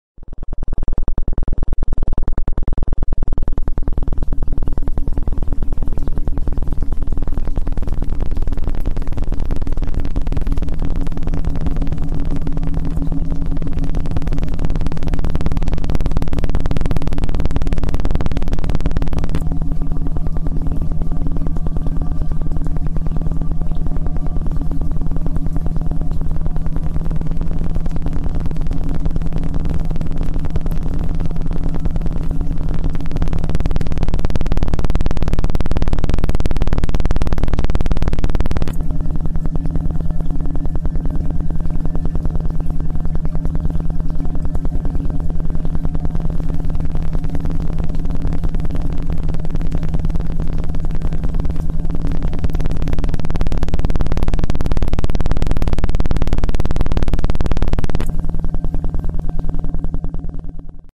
Aumenta tu memoria e inteligencia 100% con este pulso dirivido a tu cerebro y corteza prefontal.